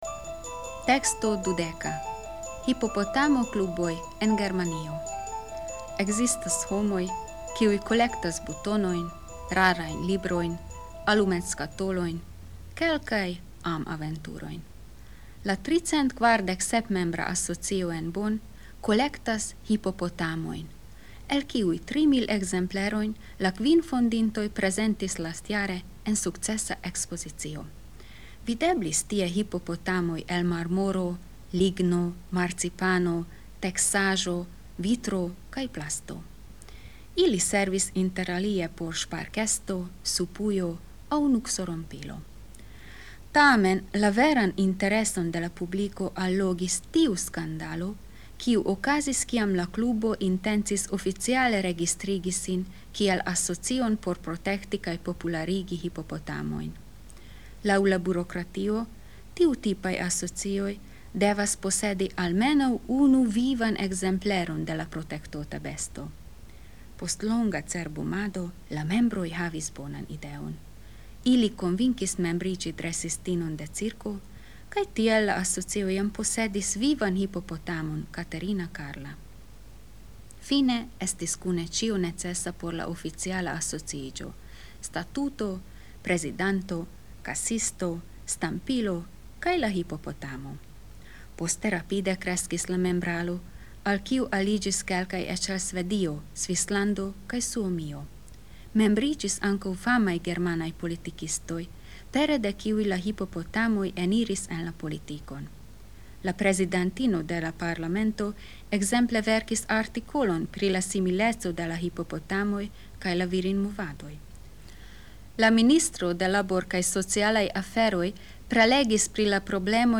La tekston laŭtlegas la aŭtorno mem.
Temo: Sonmaterialo de E-lingva teksto kun germanlingvaj kontroldemandoj